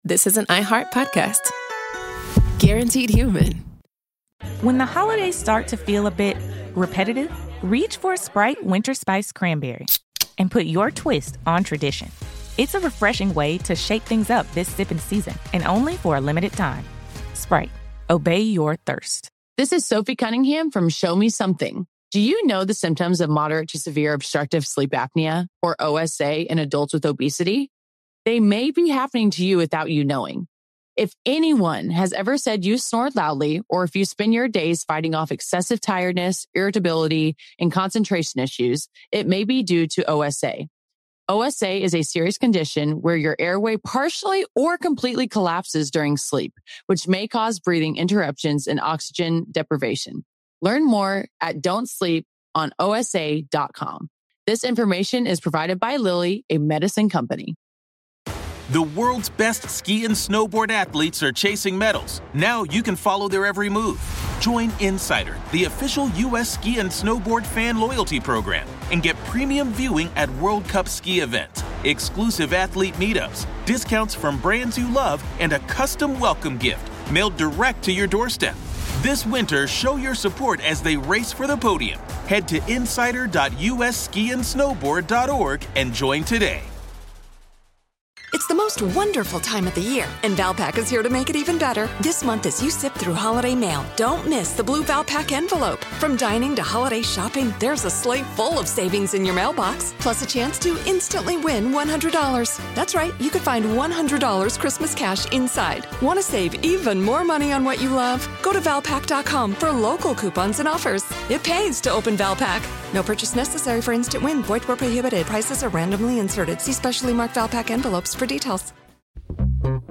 Each call traces the shape of faith, regret, and forgiveness inside a place built for punishment.